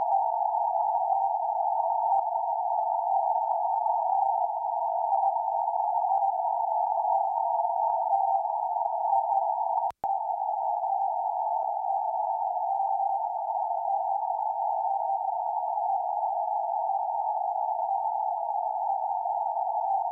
Audio comparisons between Airspy HF+ and Winradio G33DDC Excalibur Pro
No noise reduction (NR) was used.
Second 0-10 >> Winradio G33DDC Excalibur Pro
Second 10-20 >> Airspy HF+
CW
Time Signal
66.66KHz-CW-Time_Signal_Taldom_Severnyj.mp3